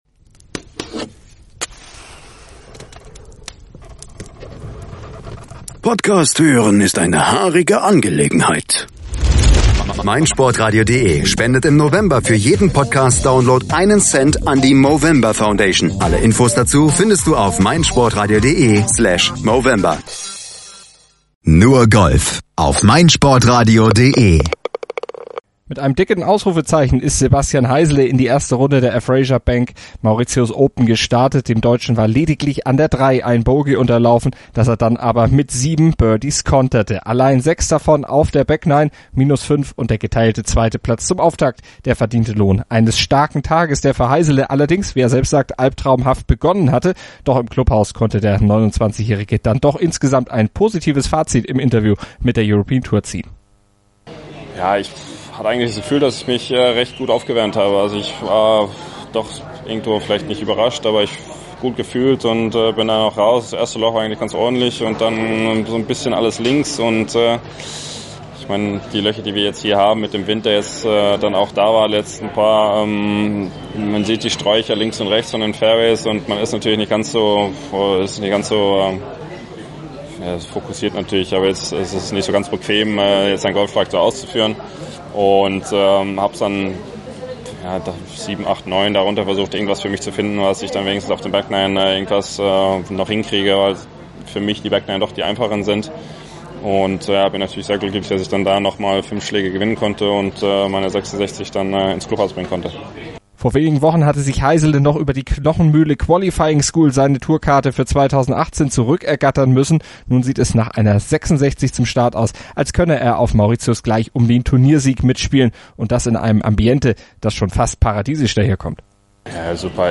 dann doch ein positives Fazit im Interview mit der European Tour